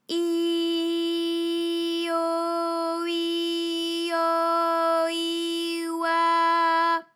ALYS-DB-001-FRA - First, previously private, UTAU French vocal library of ALYS
i_i_o_i_au_i_oi.wav